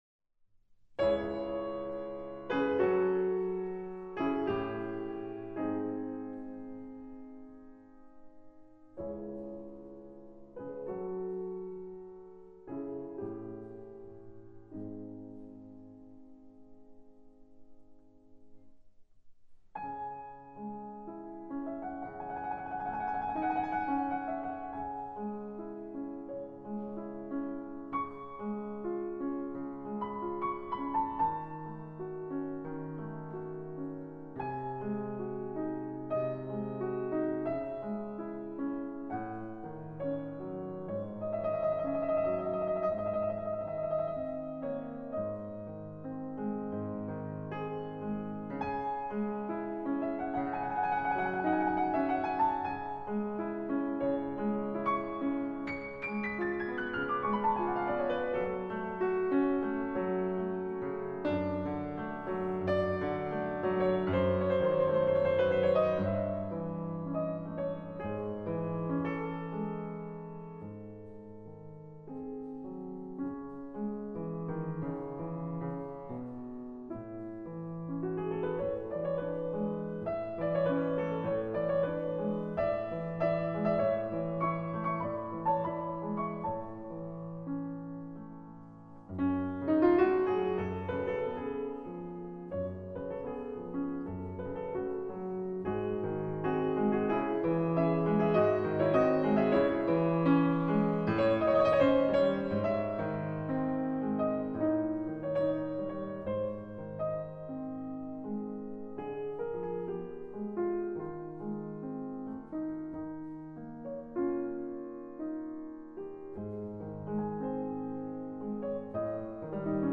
frederic_chopin_-_nocturne_in_c-sharp_minor.mp3